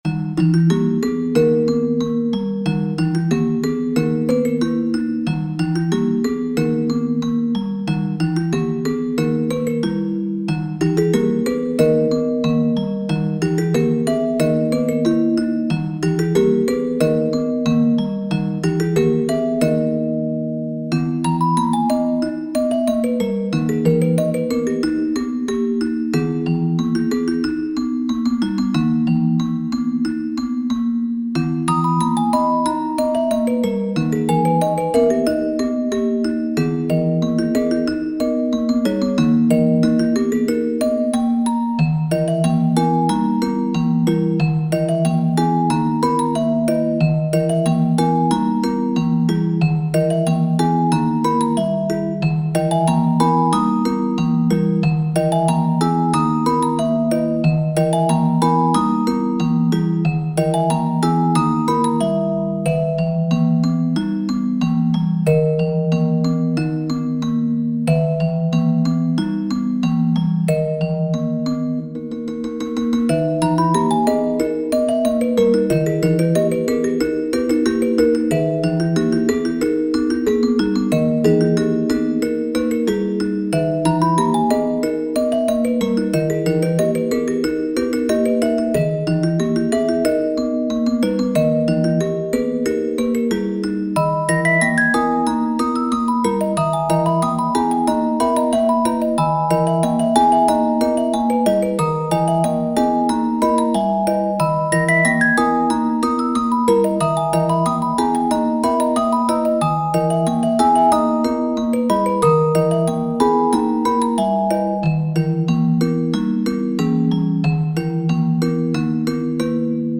タグ: オルゴール ホラー/怖い 不思議/ミステリアス 不気味/奇妙 和風 寂しい/悲しい 怪しい 暗い